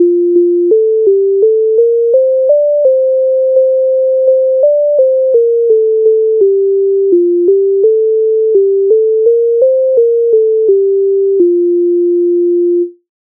MIDI файл завантажено в тональності F-dur
Ой у саду голуби гудуть Українська народна пісня з обробок Леонтовича с.97